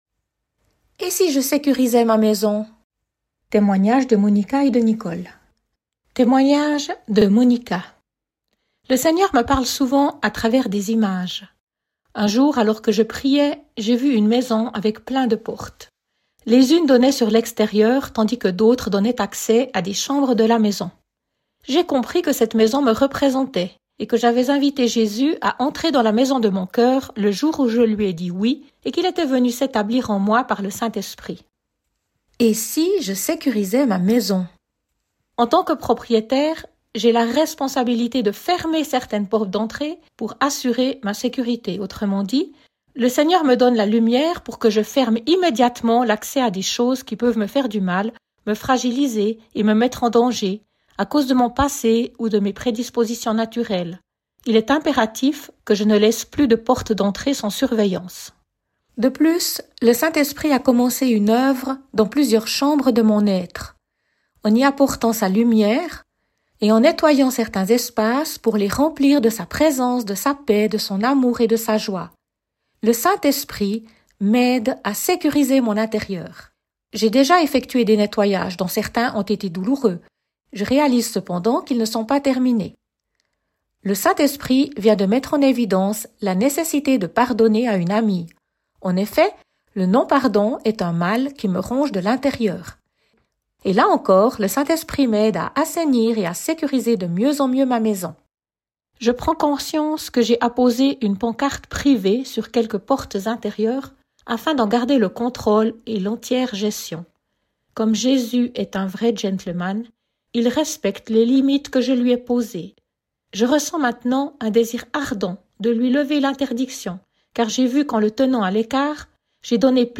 Témoignages de